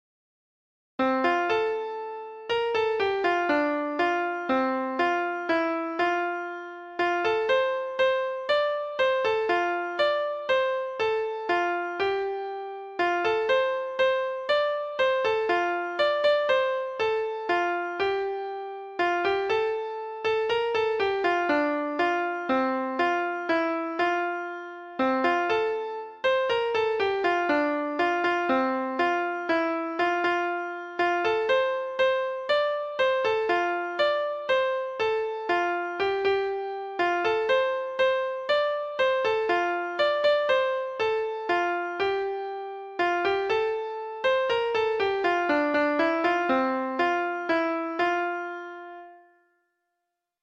Treble Clef Instrument version
Folk Songs
Traditional Music of unknown author.